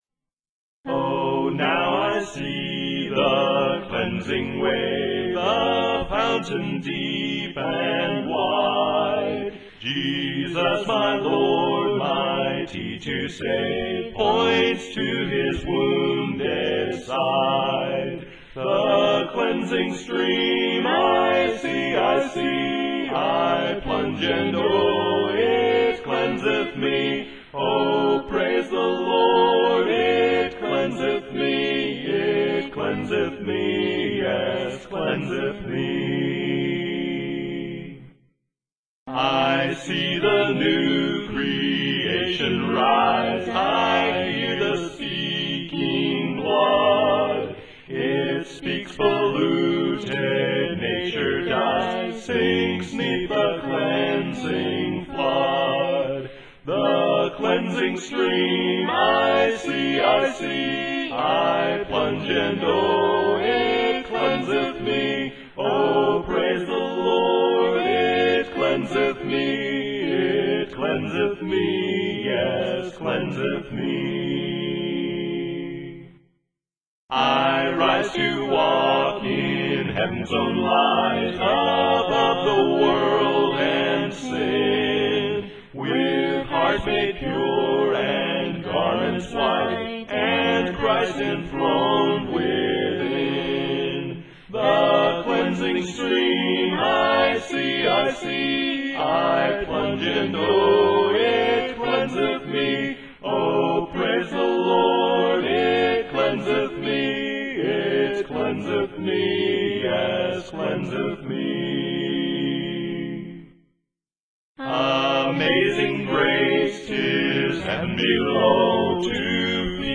Audio files: MIDI,
Key: E♭ Major